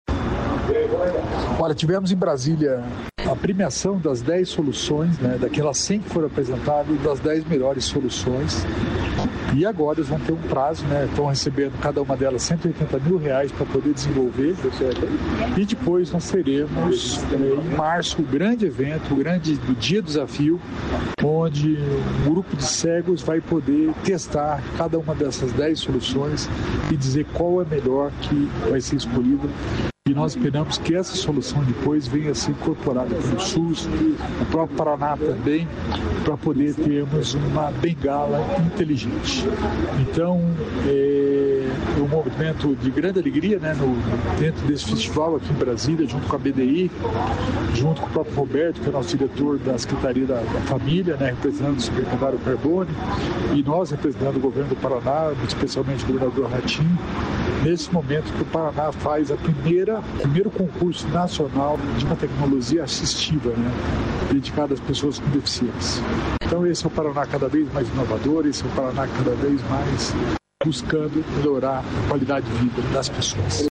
Sonora do secretário da Inovação, Alex Canziani, sobre o desafio de inovação que promove bengalas inteligentes